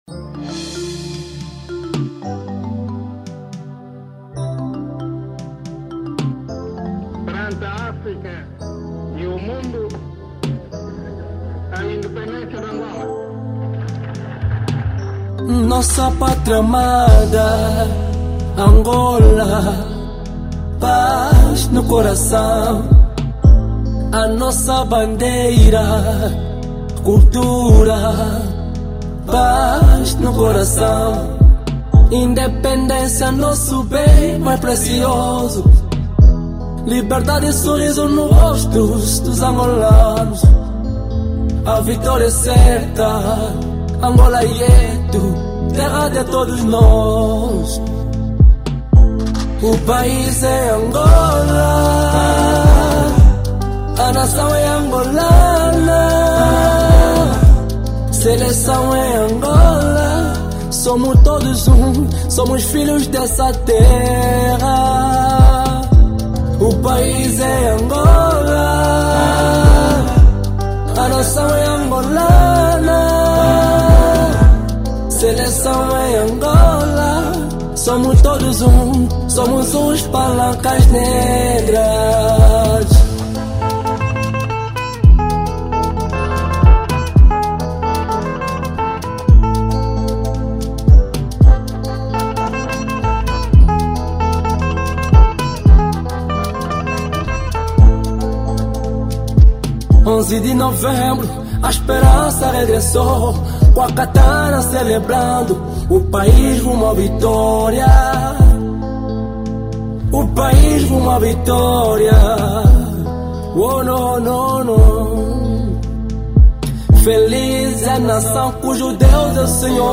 Gospel 2024